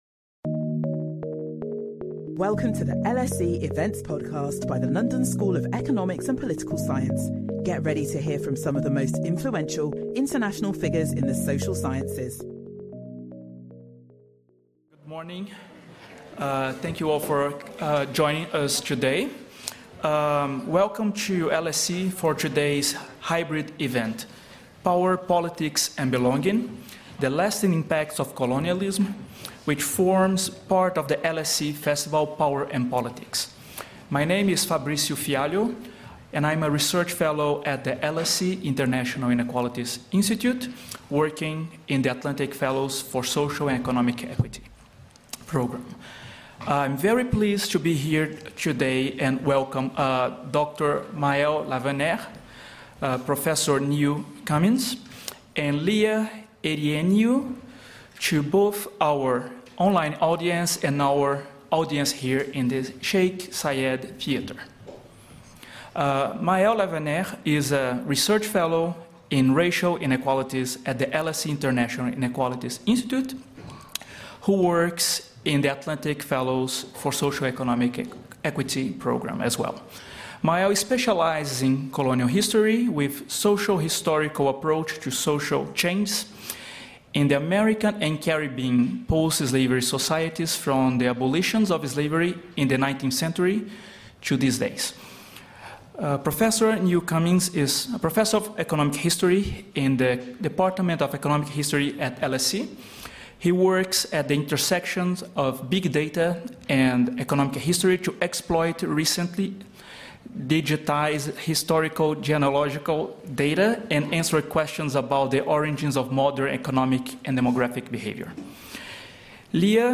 Our panel explore examples of racial and ethnic inequalities from the 19th century to the present day in an attempt to unravel the legacy of past injustices and investigate the link between power, politics, and belonging.